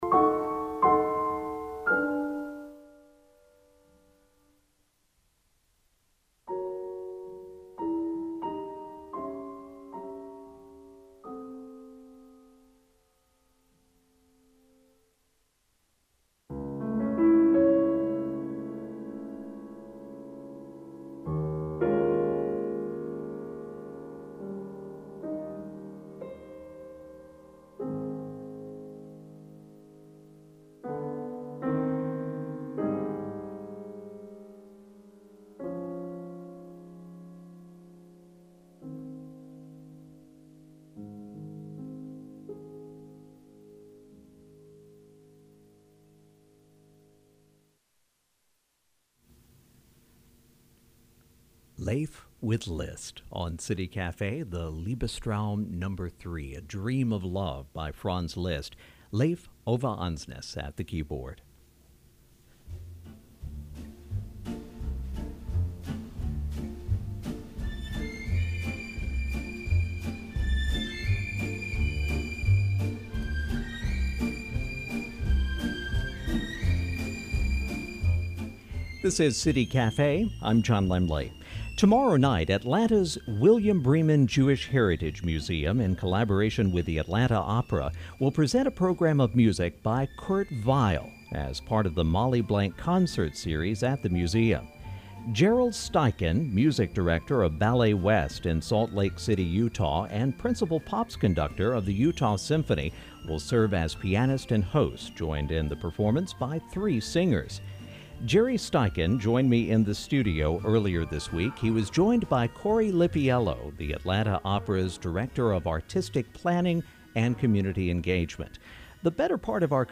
Kurt Weill: Berlin to Broadway - Radio Interview
Weill Feature Aircheck.mp3